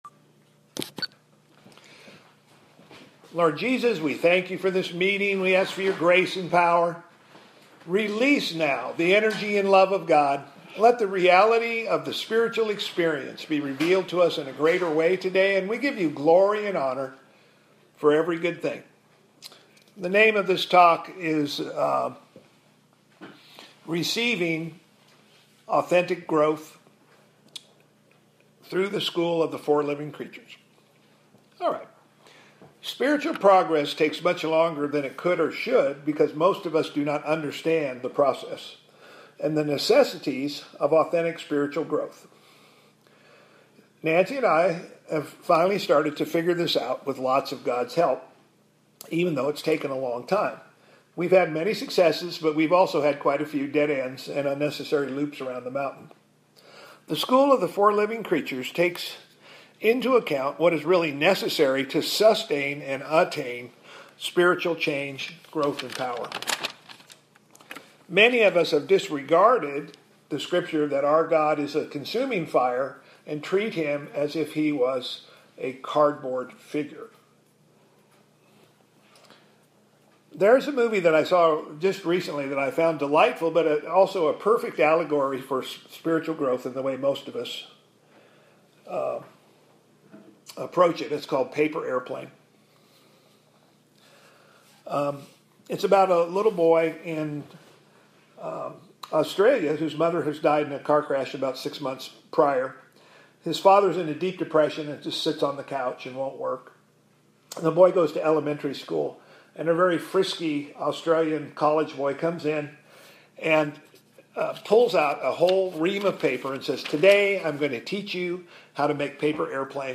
Teaching on how to grow from the School of the Four Living Creatures.